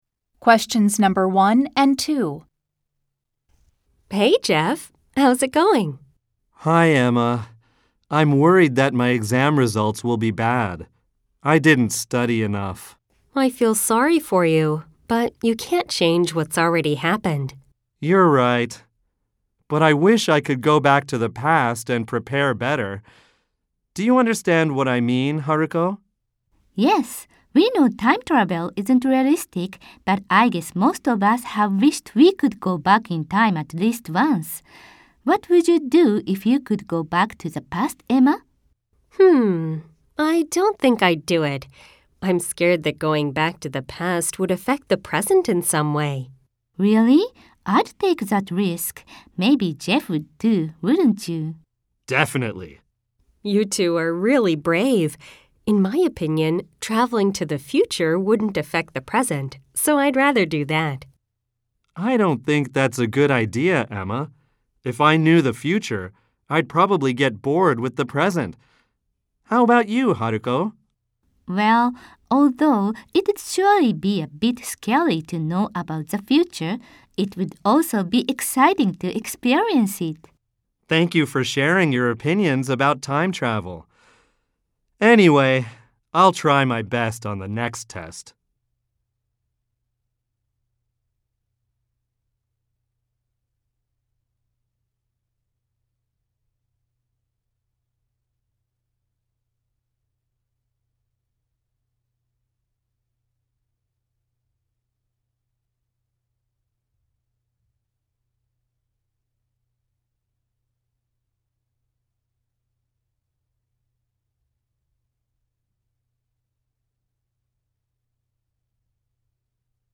設問によってイギリス英語話者や非ネイティブ話者も登場。
Lesson 9　標準 A-72